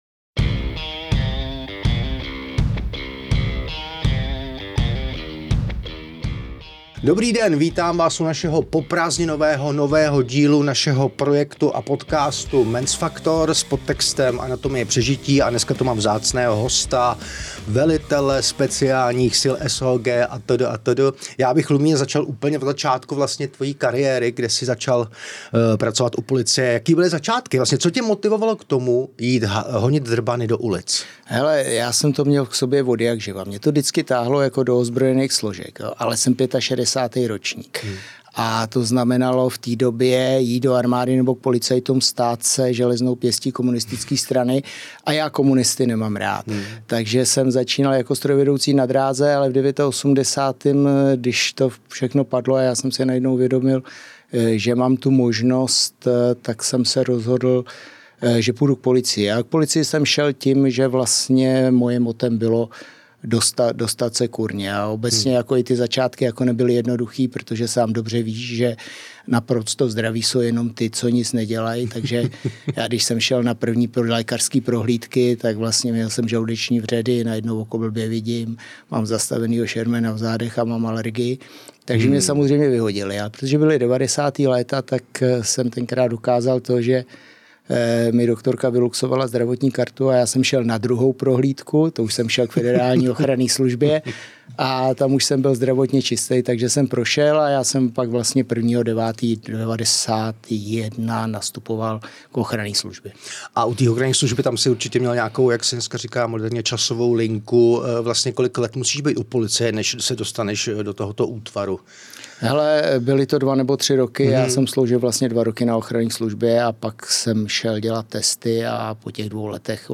V rozhovoru pro podcast Men’s Factor vzpomíná nejen na vznik legendárního útvaru, ale i na tvrdý návrat do civilního života.